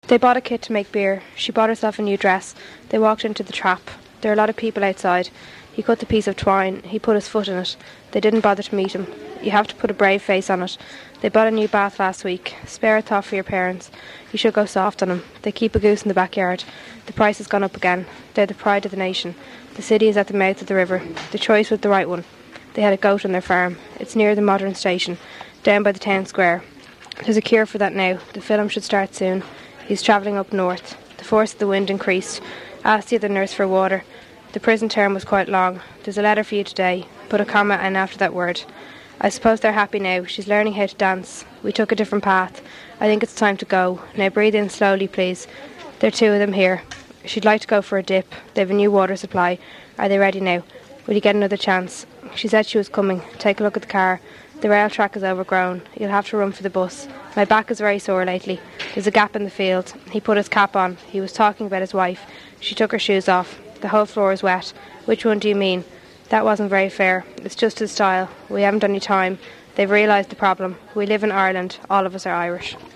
New Pronunciation of Dublin English
DUB_Whitehall_F_20.wav